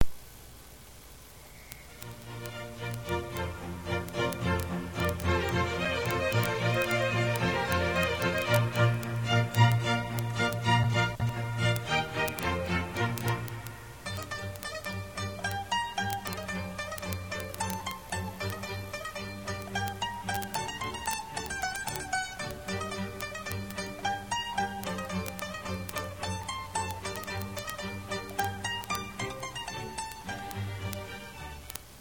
mandolin concerto